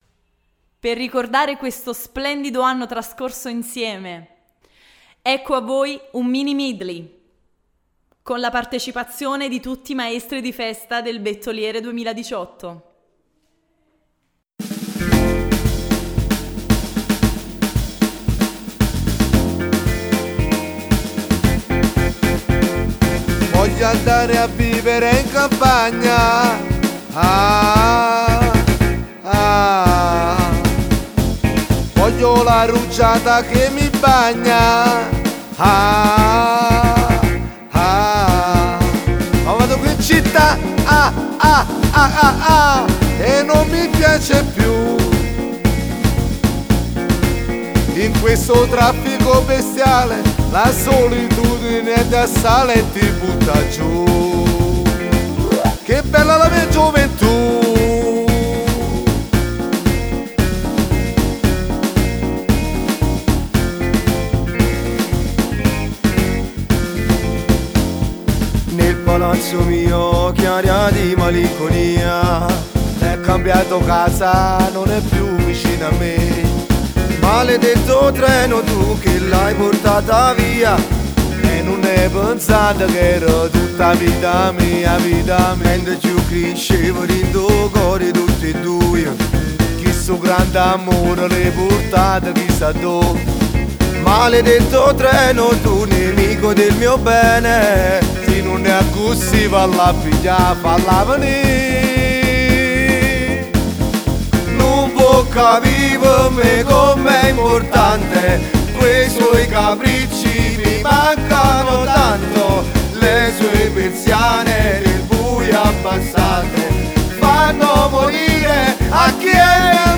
Mini medley